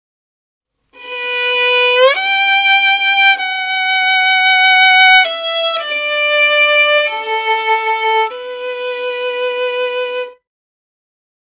Saddest_Violin_4
cinema famous film funny hearts-and-flowers motif movie sad sound effect free sound royalty free Movies & TV